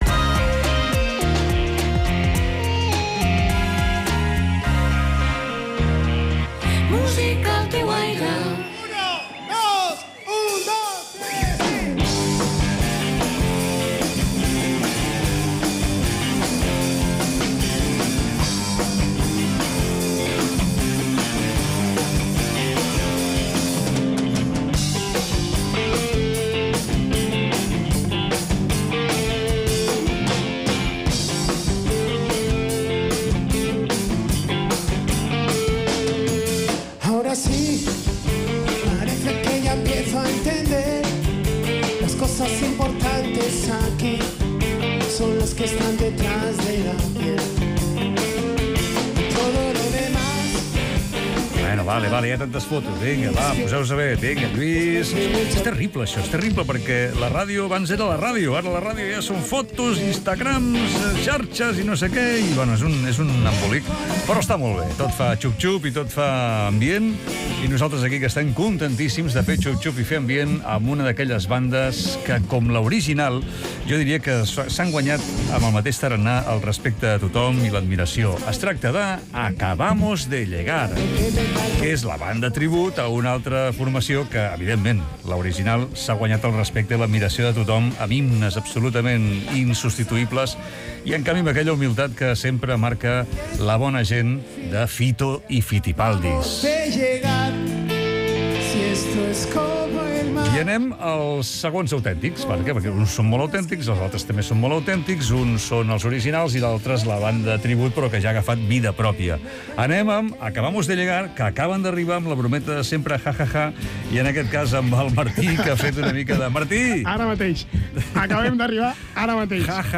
Entrevista als Acabamos de llegar 27/6/25